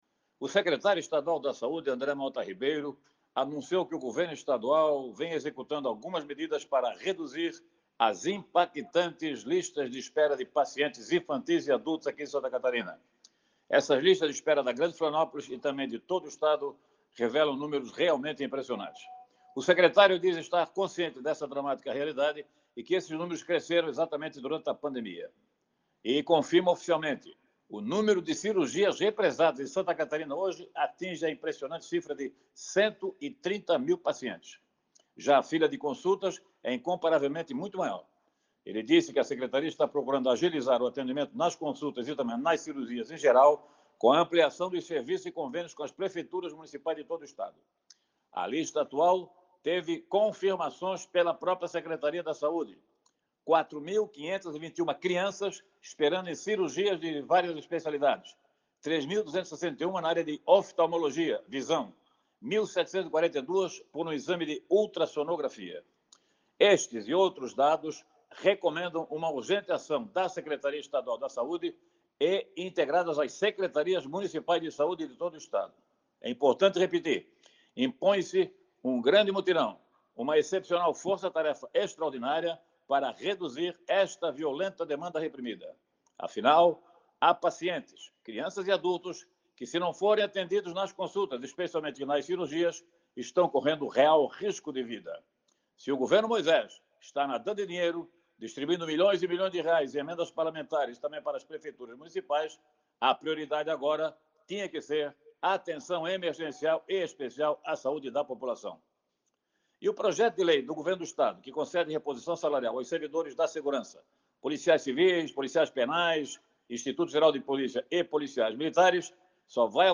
O jornalista comenta ações do governo do Estado para diminuir a espera no atendimento de crianças em hospitais